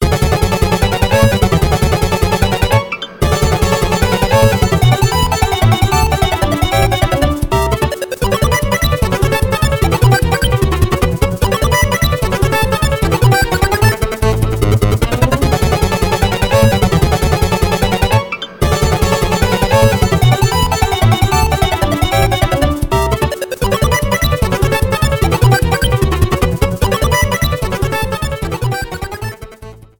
Trim and fade out